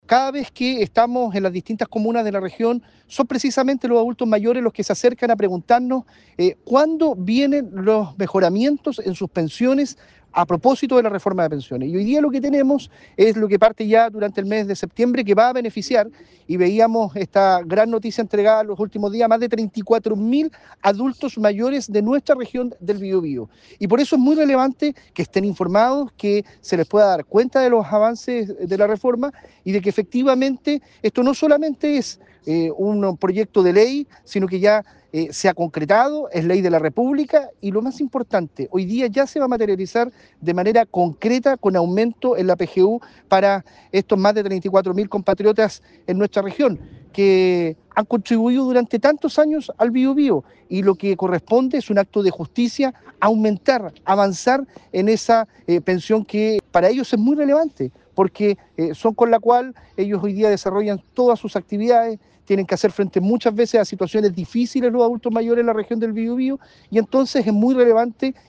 En Concepción: Ministro Boccardo participó en conversatorio sobre el inicio del pago de beneficios de la Reforma de Pensiones - Radio UdeC